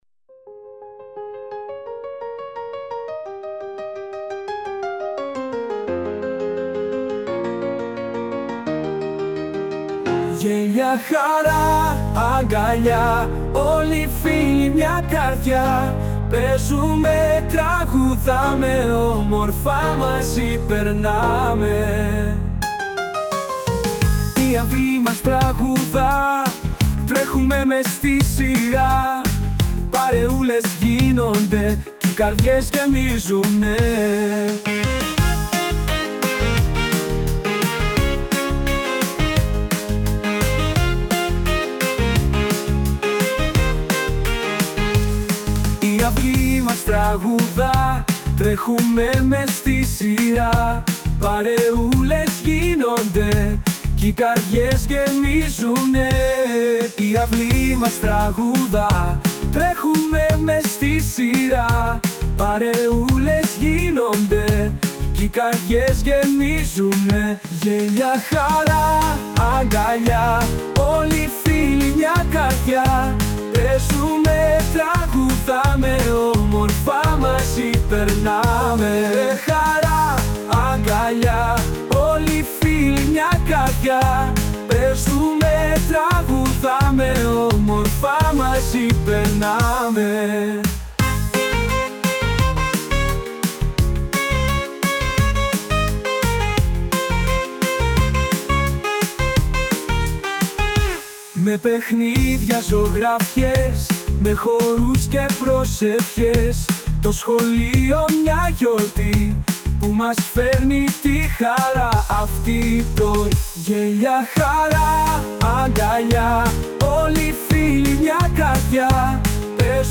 που δημιουργήθηκε με την εφαρμογή τεχνητής νοημοσύνης